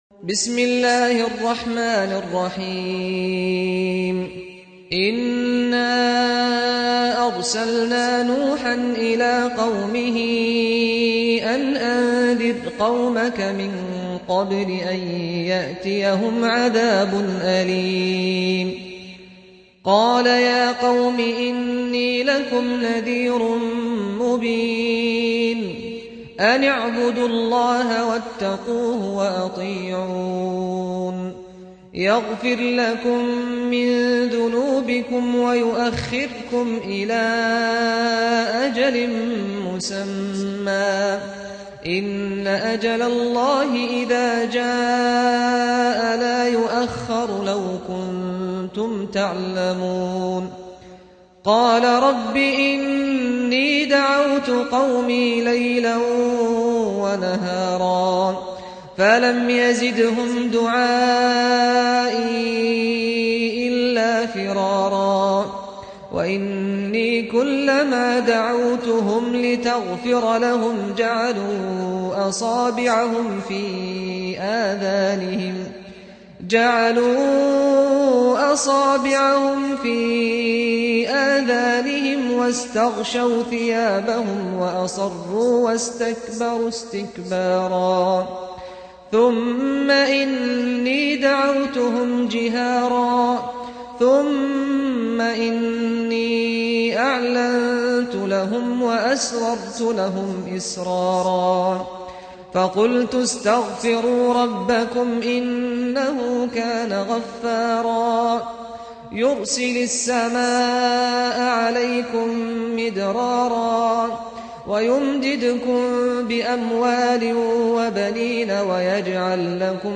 سُورَةُ نُوحٍ بصوت الشيخ سعد الغامدي